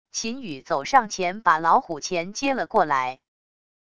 秦宇走上前把老虎钳接了过来wav音频生成系统WAV Audio Player